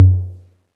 cch_perc_tom_low_wilfred.wav